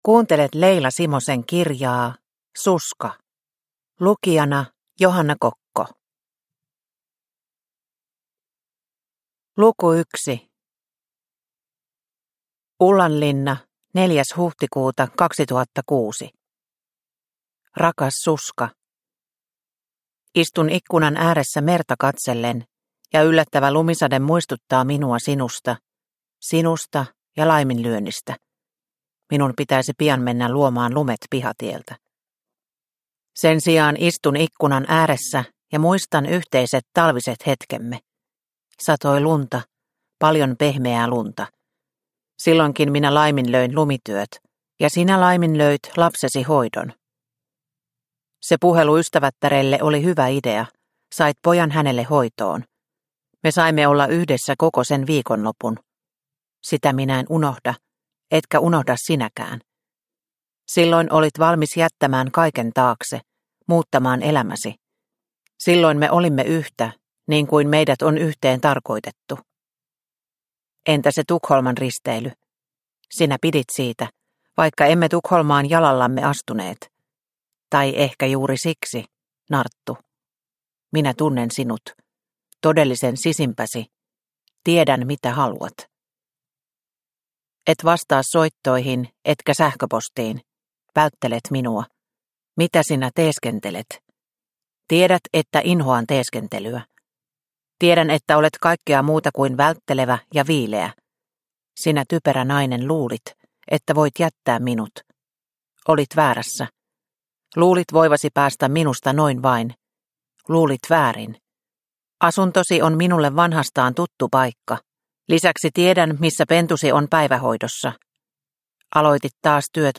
Suska – Ljudbok – Laddas ner